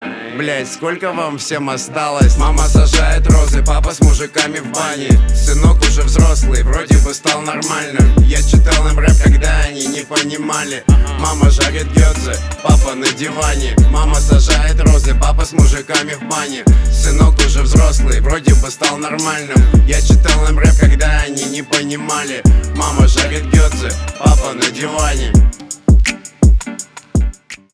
русский рэп
битовые , матерные , басы , жесткие